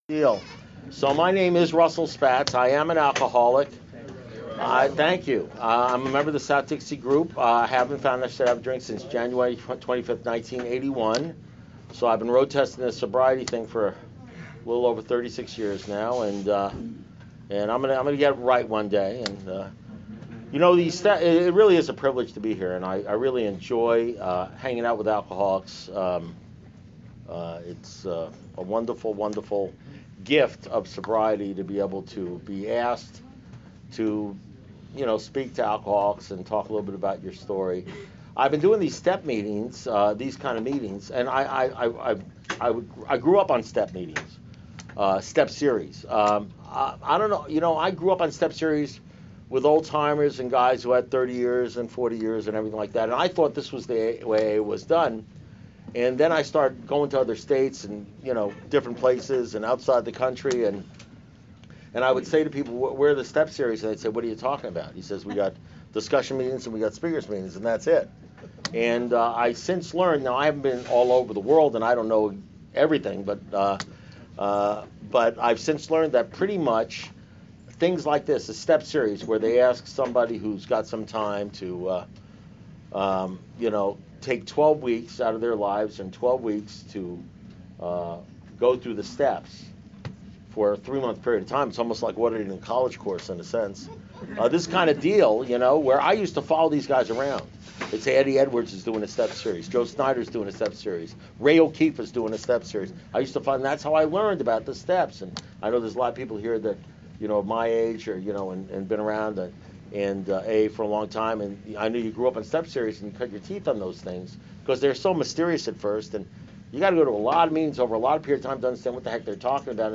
Alcoholics Anonymous Speaker Recordings